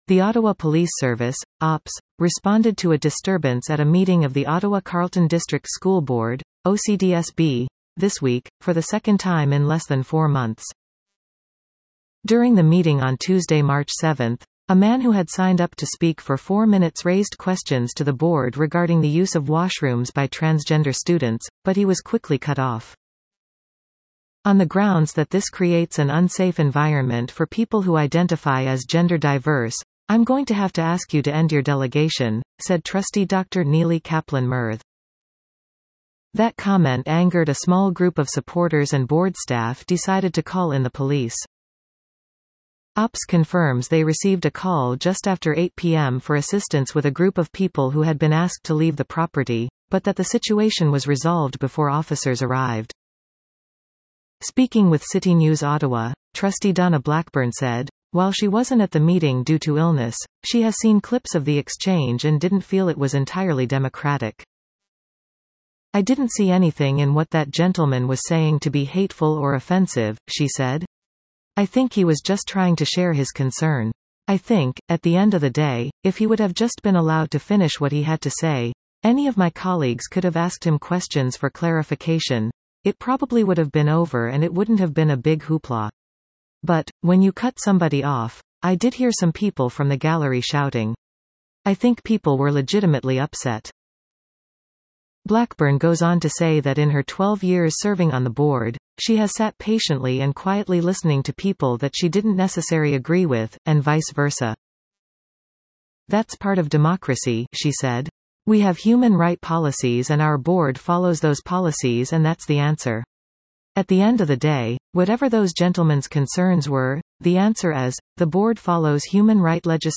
A man who had signed up to speak was quickly cut off when he raised questions over the use of washrooms by transgender students, which angered a small group in the gallery.